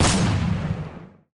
autoshotgun_fire.ogg